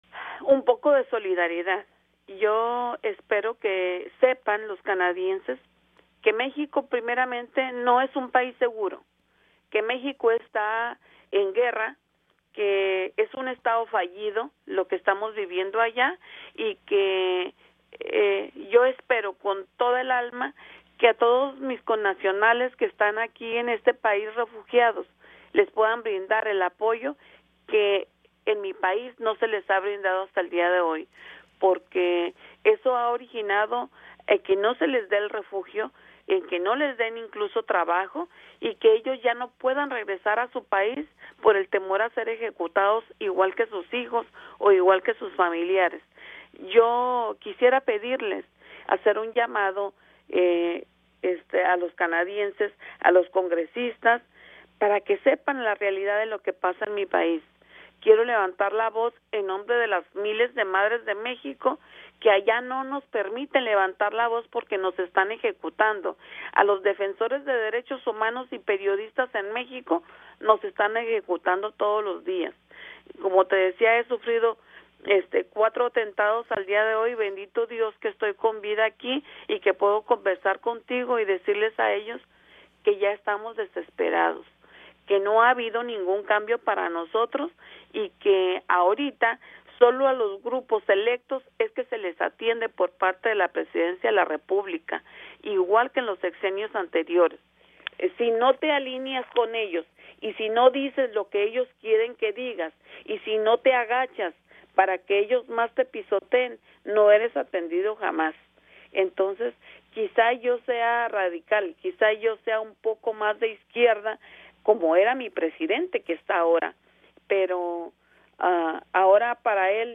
Ella cuenta en entrevista con Radio Canadá Internacional la crónica de su desaparición.